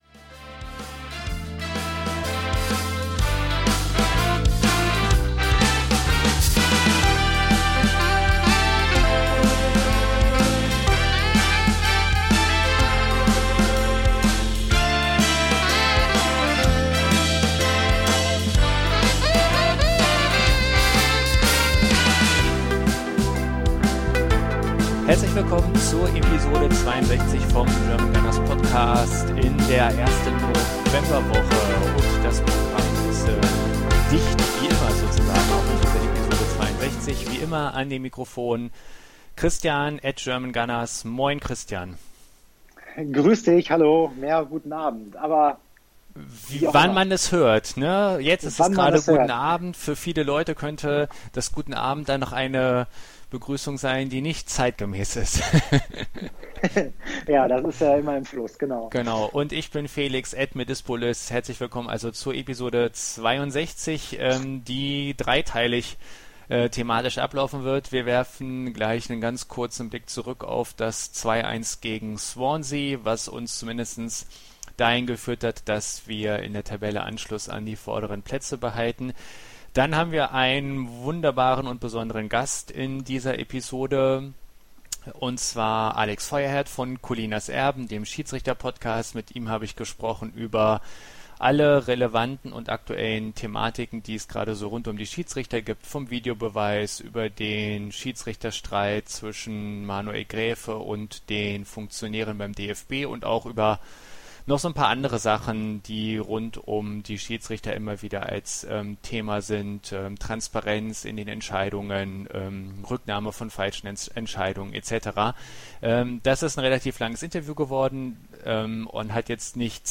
In der heutigen Ausgabe des GGPodcast blicken wir über den Tellerrand Arsenals hinaus und nehmen uns in einem ausführlich Interview mit den Experten von Collinas Erben der Thematik Videobeweis und Schiedsrichterleistung an.